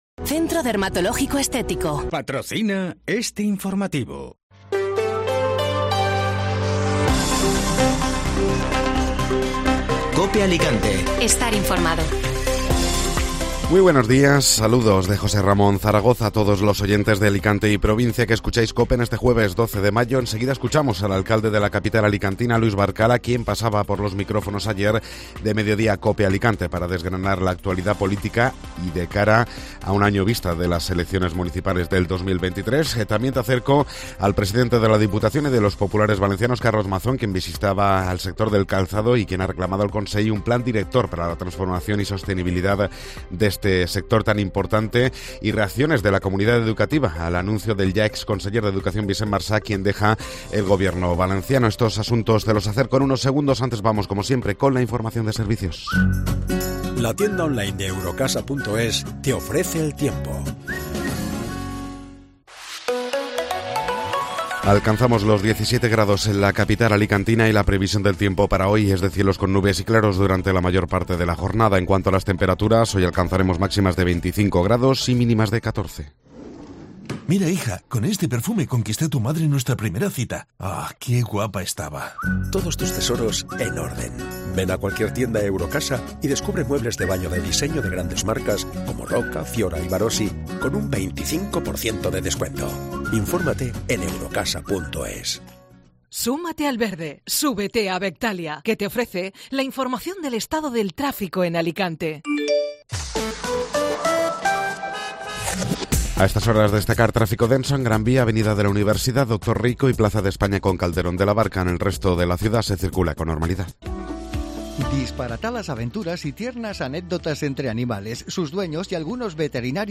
El alcalde de Alicante Luis Barcala pasaba ayer por los micrófonos de Mediodía Cope Alicante para hablar de la actualidad politica y el balance de los tres años de gobierno